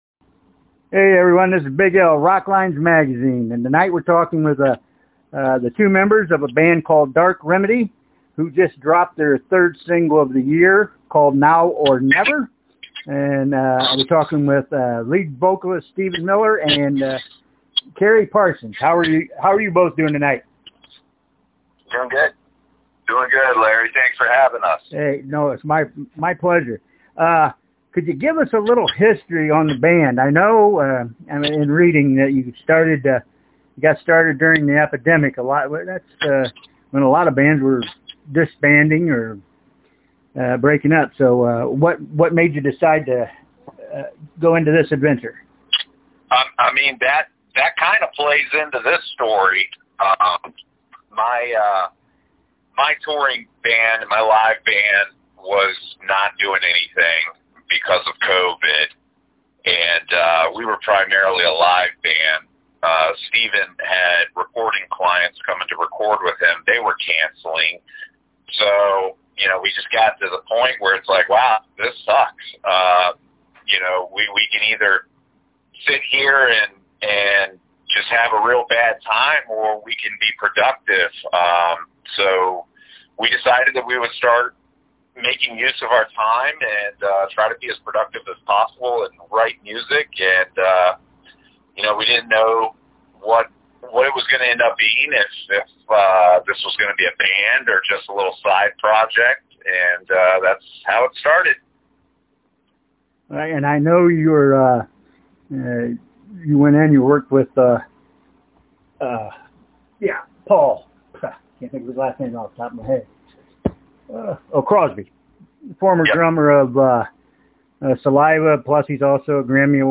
dark-remedy-interview-.mp3